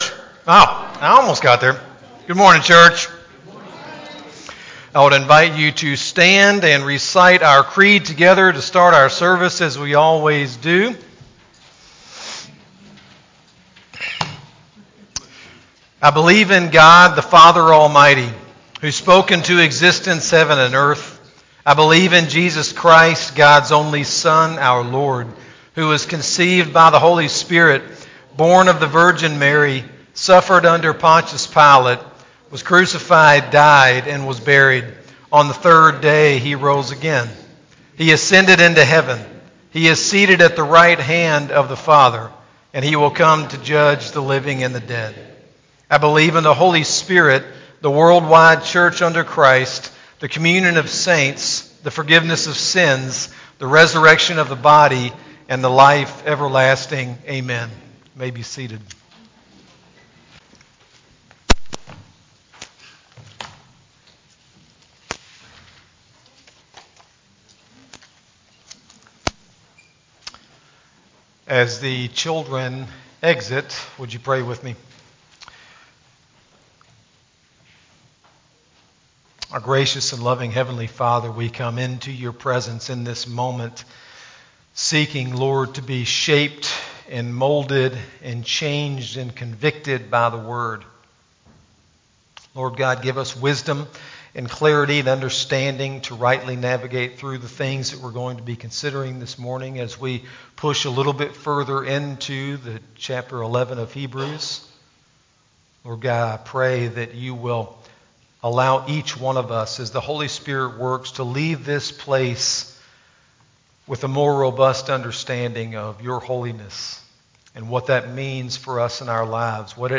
Sermon-8-18-24-CD.mp3